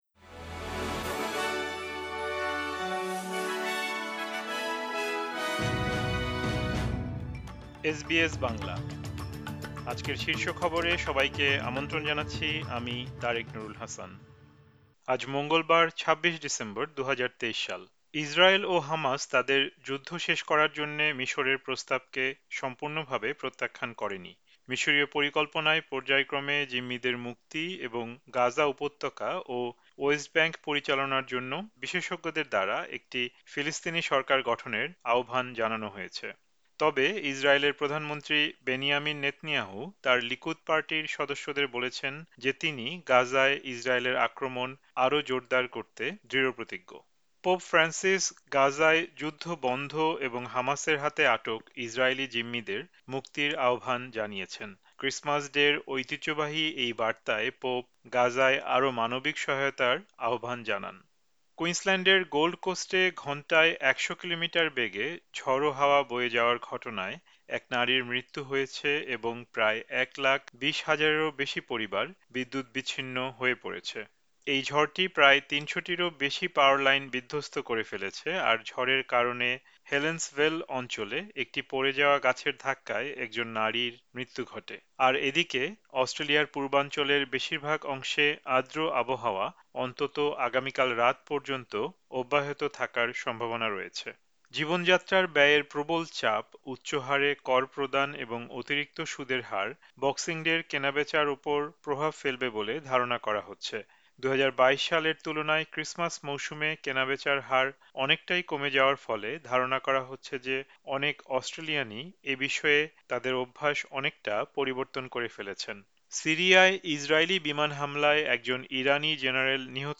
এসবিএস বাংলা শীর্ষ খবর: ২৬ ডিসেম্বর, ২০২৩